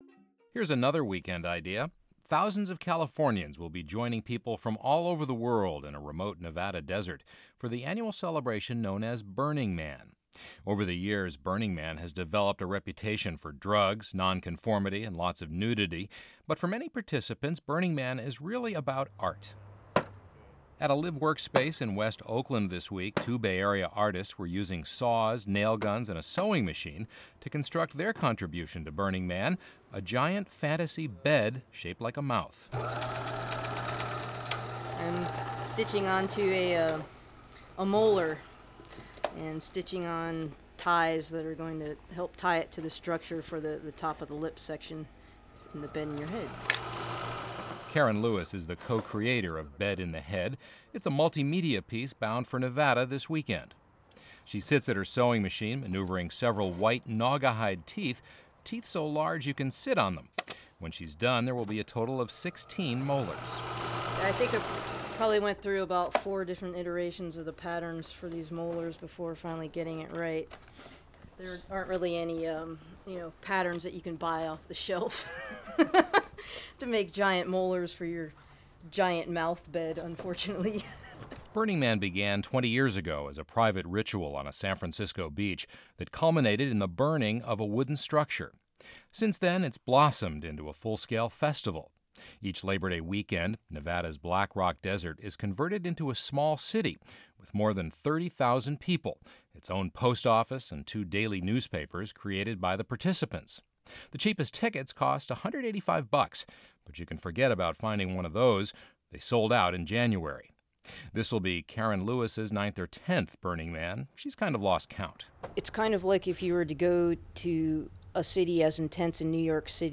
Radio
bman-radiointerview.mp3